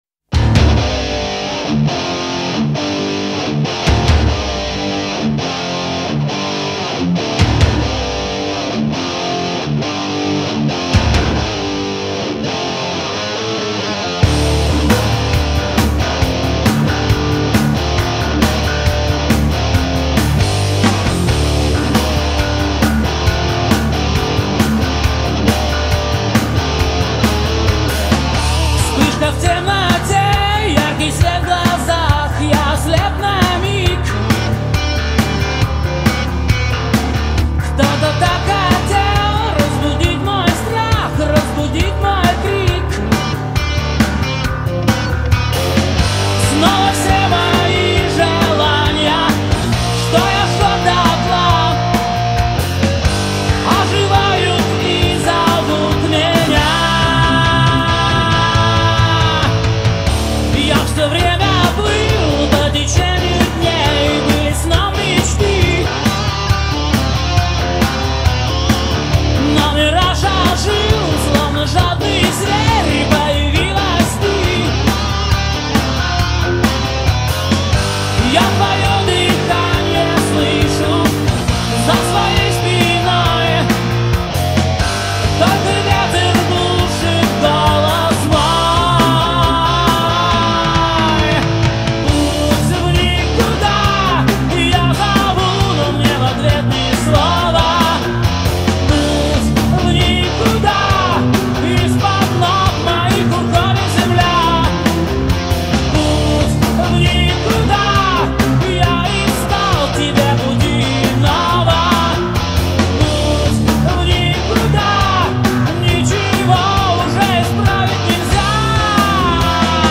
это мощный и глубокий трек